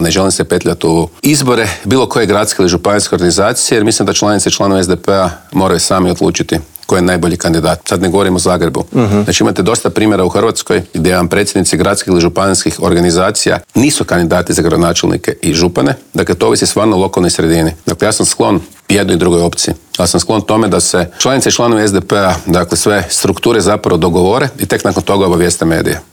"Na idućim parlamentarim izborima SDP će biti prvi, kandidirao sam se za predsjednika stranke da budem premijer", otkrio nam je u Intervjuu tjedna Media servisa predsjednik najjače oporbene stranke Siniša Hajdaš Dončić.